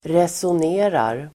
Uttal: [reson'e:rar]